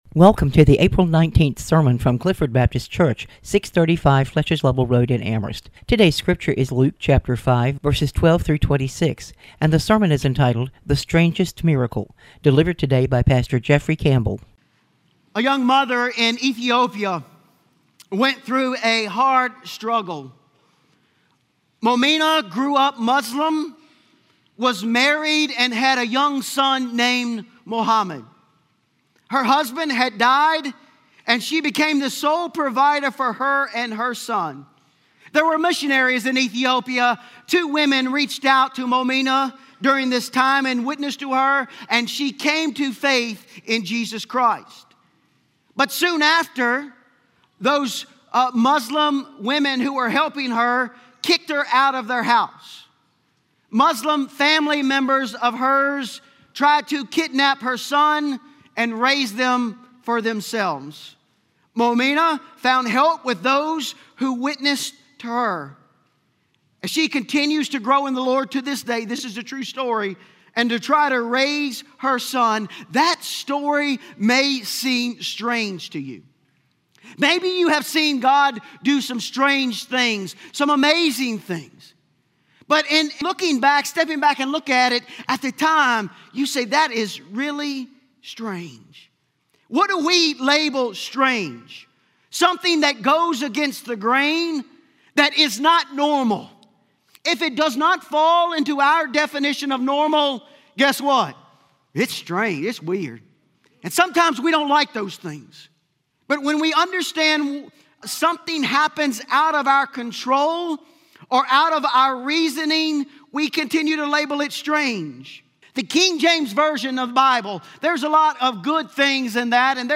Clifford Baptist Luke 5:12-26, "The Strangest Miracle" Apr 20 2026 | 00:31:37 1x 00:00 / 00:31:37 Subscribe Share Spotify RSS Feed Share Link Embed